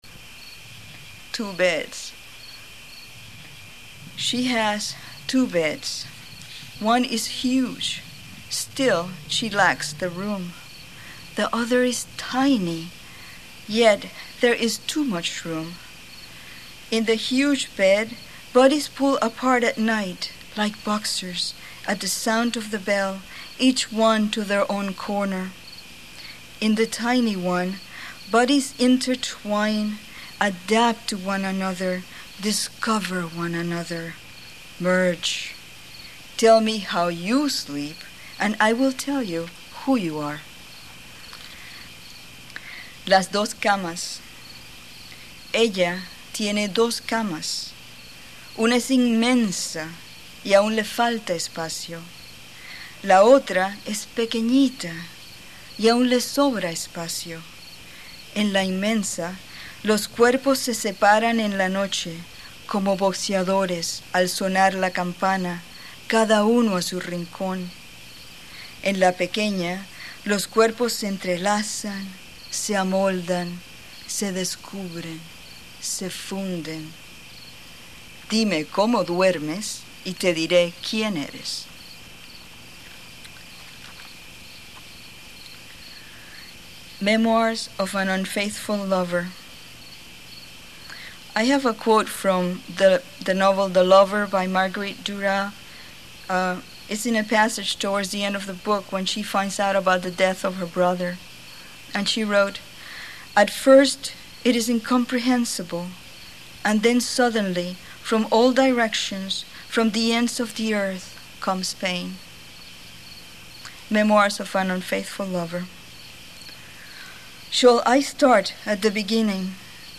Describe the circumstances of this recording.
at Hill-Stead Museum’s Sunken Garden Poetry Festival in Farmington, CT.